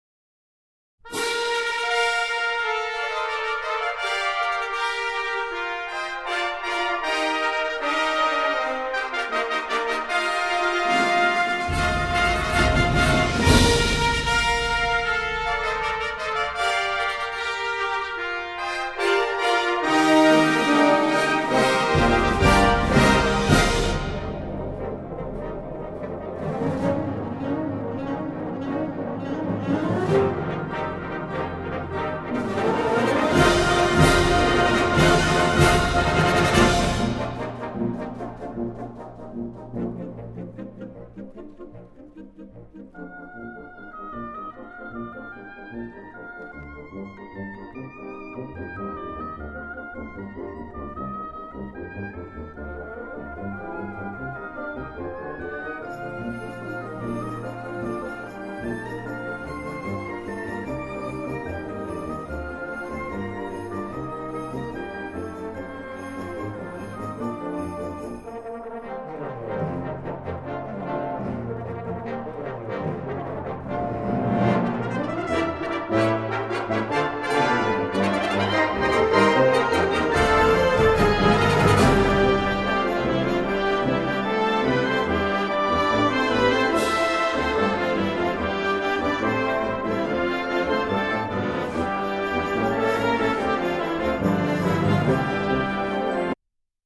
Gattung: Ouvertüre
Besetzung: Blasorchester